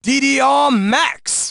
Root > content > SFX & Announcers > DDR Extreme SFX